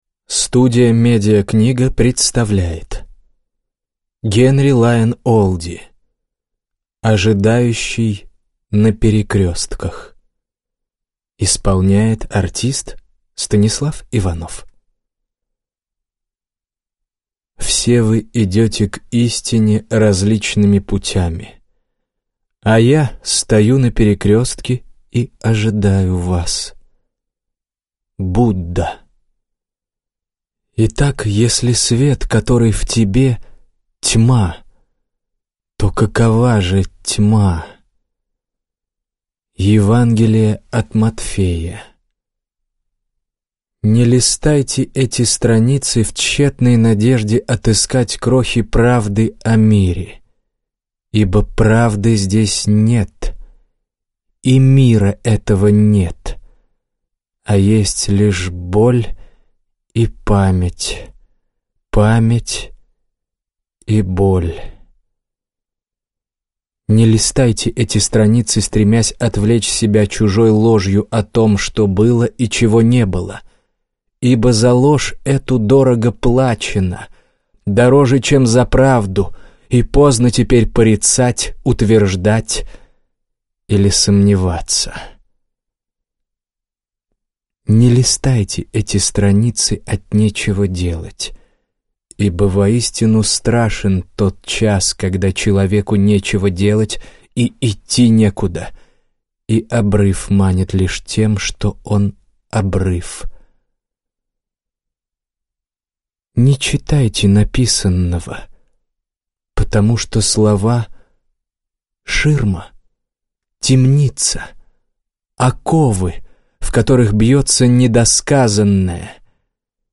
Аудиокнига Ожидающий на Перекрестках | Библиотека аудиокниг